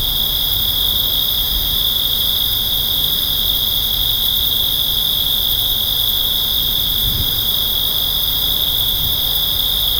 Night crickets + soft wind
night-crickets--soft-wind-mbfbi7qp.wav